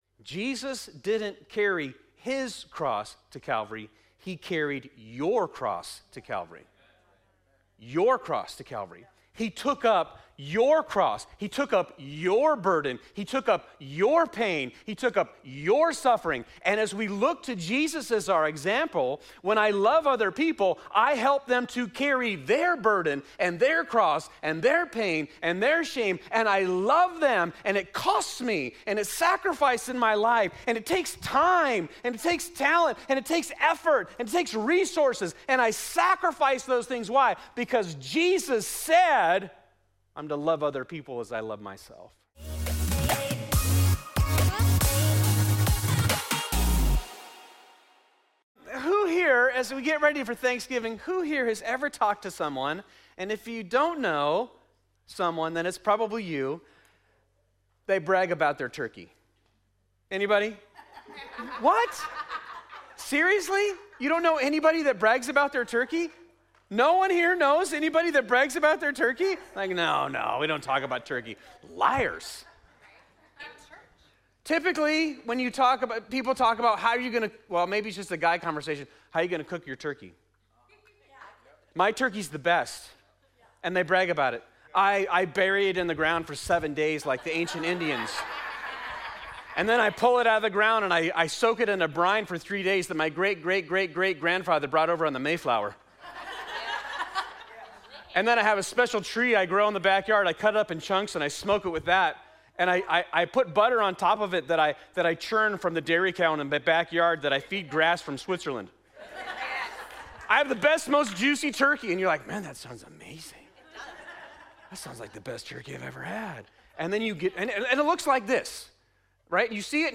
This is part 4 of "Thanks & Giving," our series at Fusion Christian Church where we reflect on how to be both grateful and generous in our lives. In this sermon